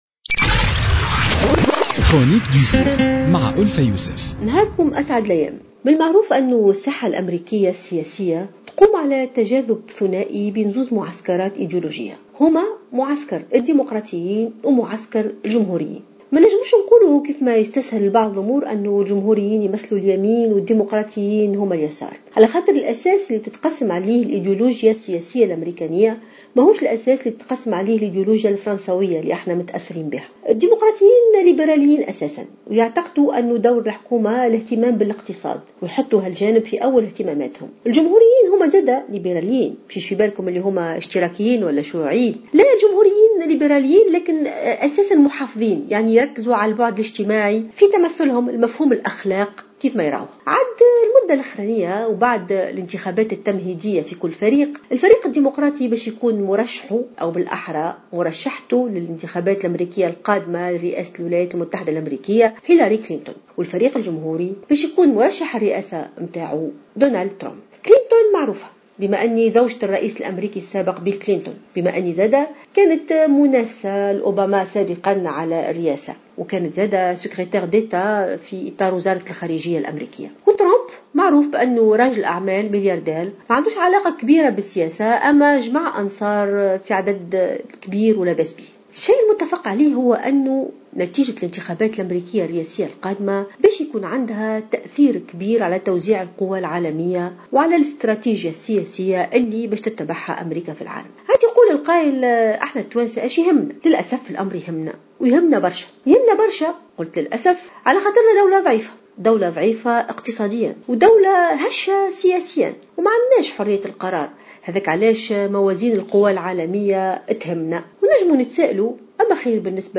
اعتبرت الكاتبة ألفة يوسف في افتتاحيتها اليوم لـ "الجوهرة اف أم" أن المرشحين هيلاري كلنتون عن الديمقراطيين ودونالد ترامب عن الجمهوريين بالانتخابات الأميركية، هما وجهان لعملة واحدة رغم أنهما من حزبين مختلفين.